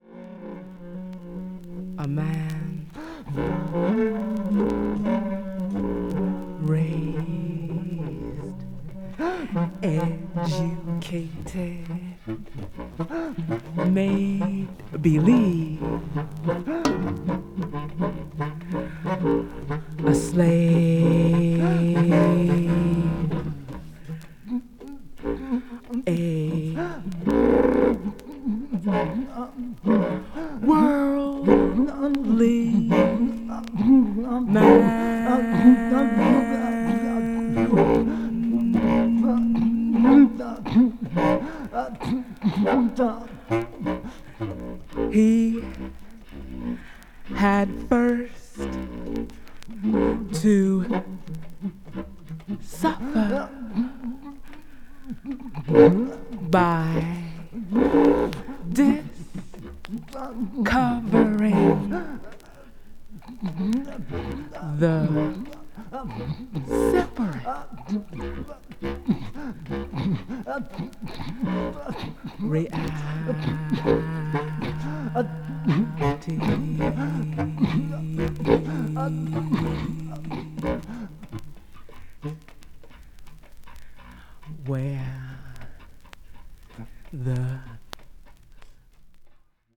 avant-jazz   free improvisation   free jazz